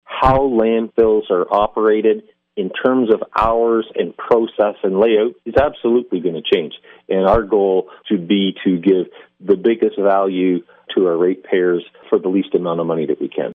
Mayor Brent Devolin says he expects the township’s landfill operations to change.